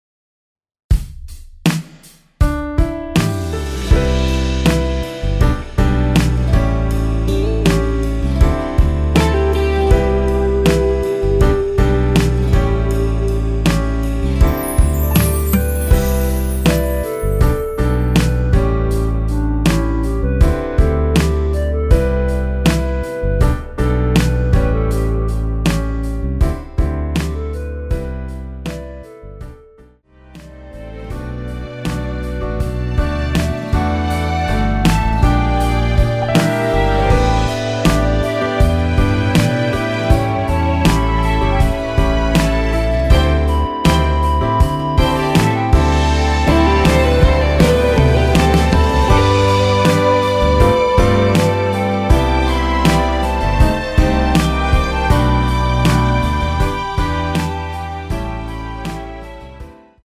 원키에서(+3)올린 멜로디 포함된 MR 입니다.(미리듣기 참조)
앞부분30초, 뒷부분30초씩 편집해서 올려 드리고 있습니다.
중간에 음이 끈어지고 다시 나오는 이유는